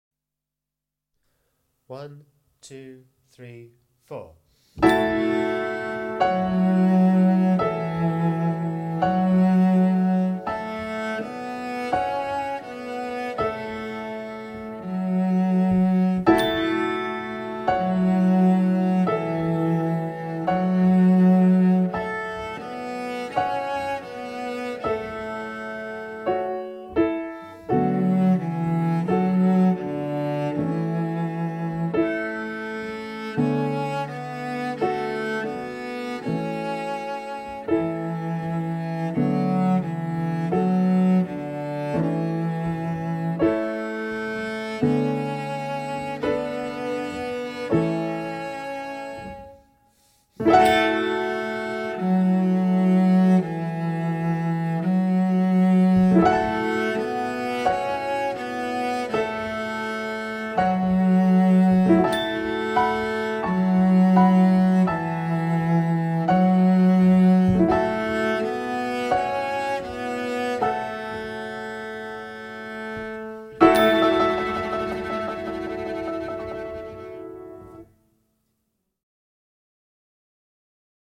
25 Peace garden (Cello)